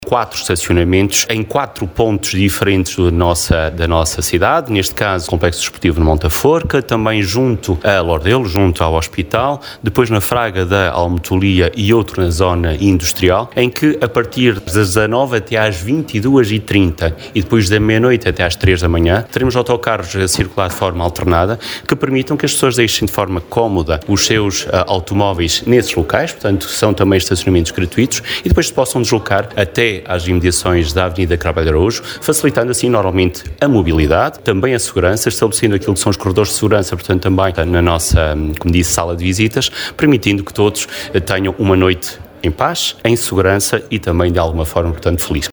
Alexandre Favaios, presidente da Câmara Municipal de Vila Real, refere que serão criados parques de estacionamento exteriores nas entradas de Vila Real, situados na Fraga da Almotolia, Zona Industrial, Zona envolvente ao Hospital e Monte da Forca, permitindo aos visitantes deixar as viaturas fora da malha urbana e aceder ao centro através de transportes públicos urbanos especialmente reforçados para o efeito: